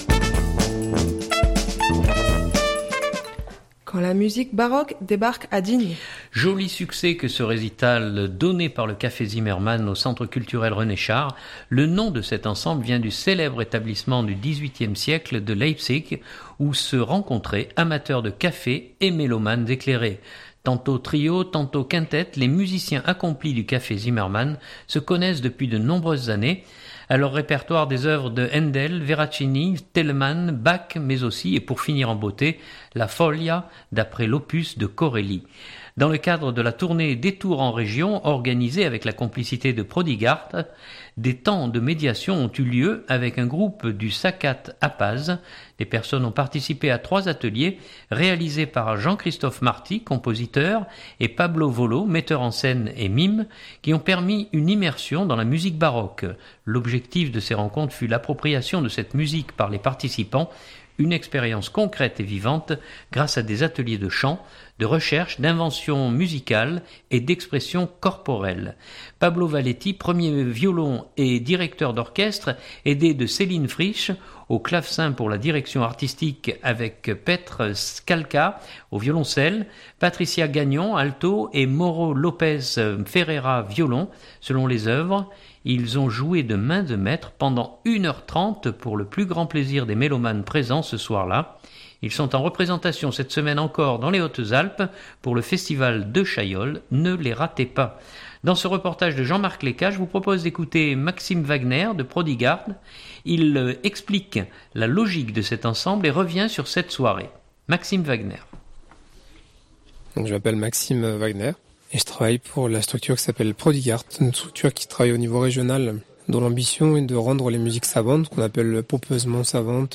Dans ce reportage